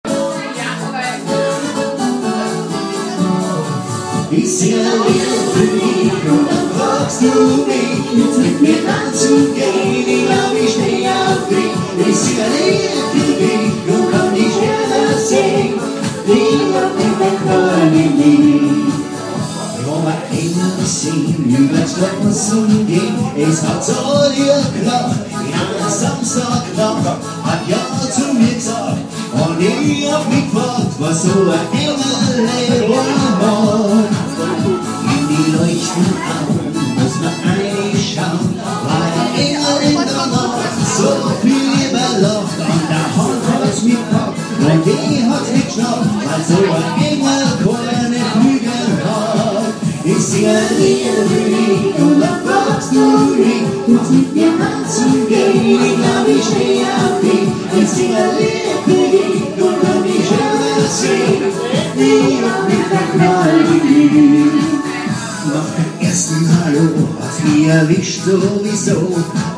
• Duo/Trio/Quartett